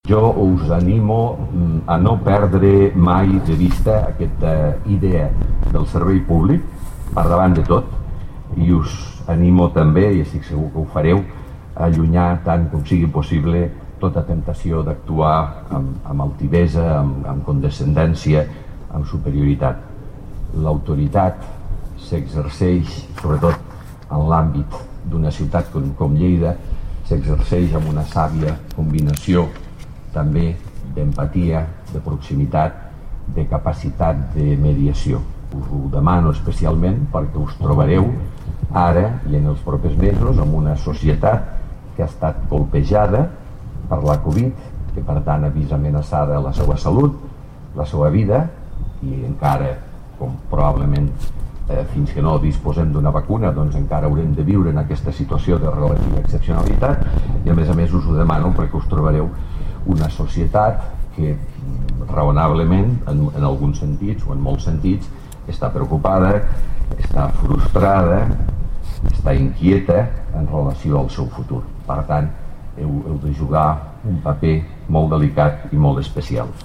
El Baluard de la Reina de la Seu Vella acull l’acte de presa de possessió amb el qual la policia local de Lleida arriba als 230 agents
tall-de-veu-del-paer-en-cap-miquel-pueyo-sobre-la-incorporacio-de-31-nous-i-noves-agents-de-la-guardia-urbana